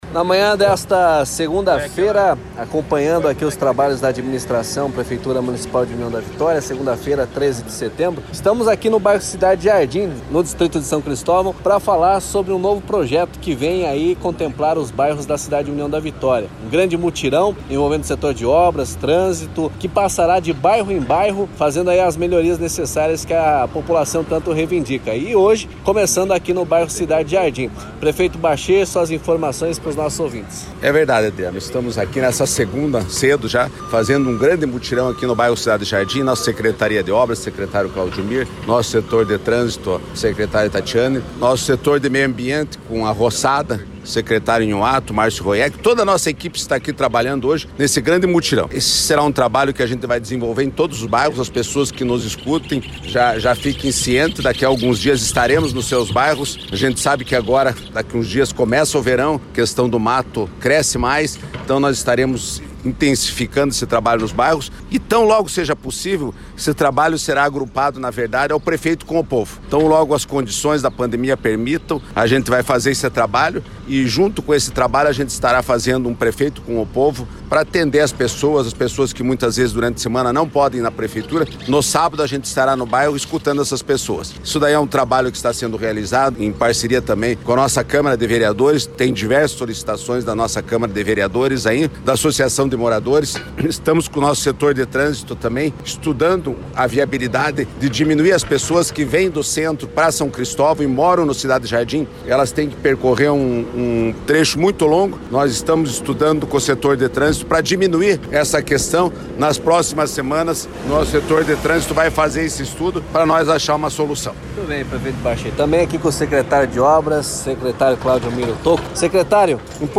Acompanhe o áudio do prefeito junto do secretário de Obras: